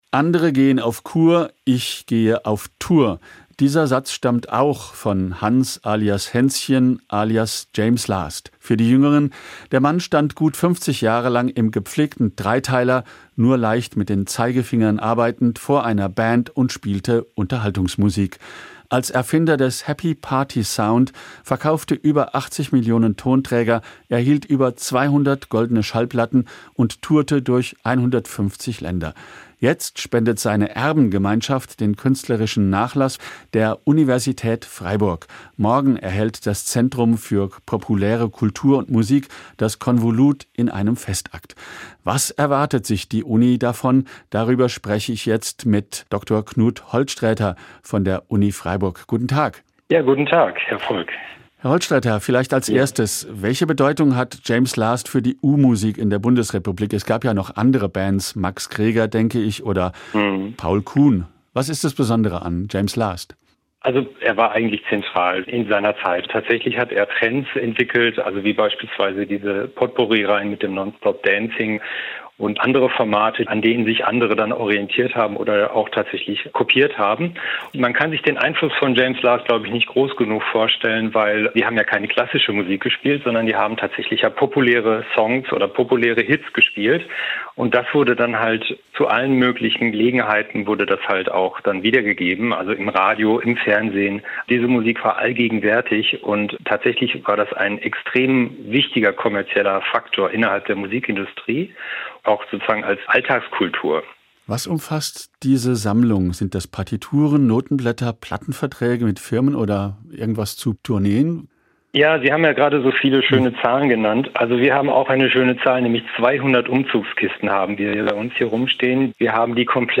Die Erben des legendären Bandleaders James Last haben dem Zentrum für populäre Kultur und Musik der Uni Freiburg dessen Nachlass vermacht. Im Gespräch mit SWR Kultur